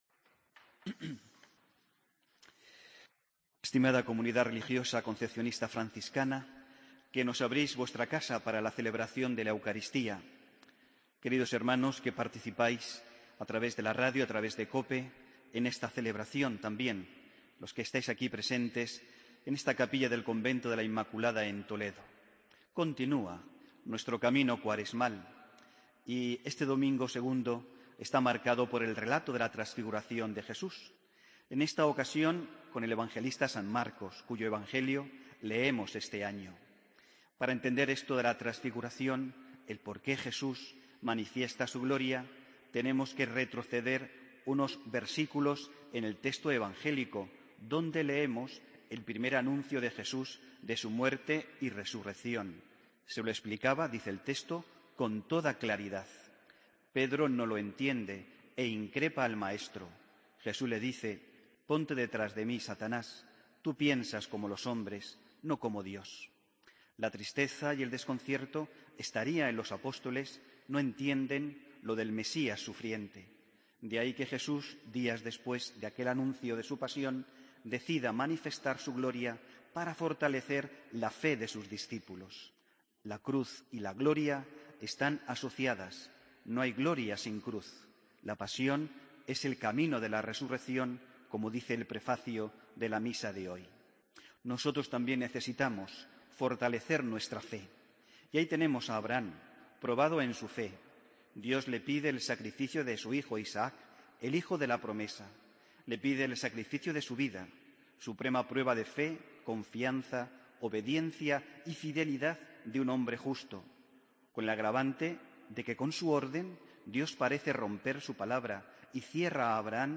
Homilía del domingo 1 de marzo de 2015